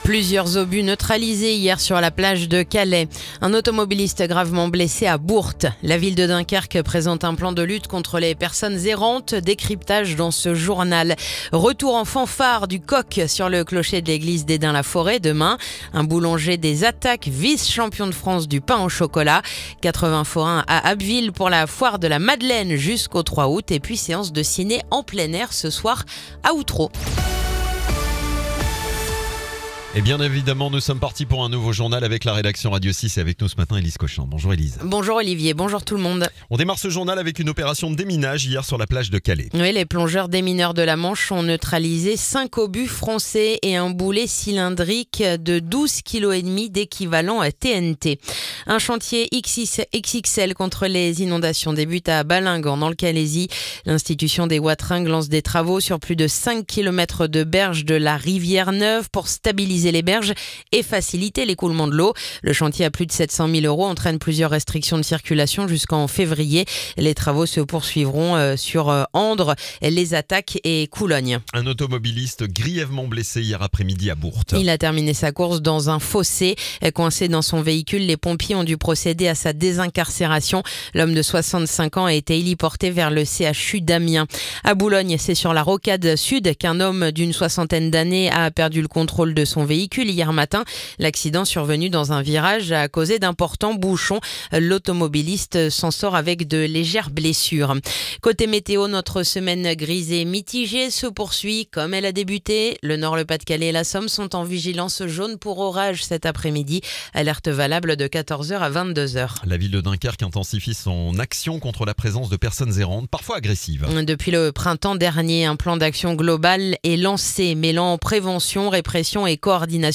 Le journal du mercredi 23 juillet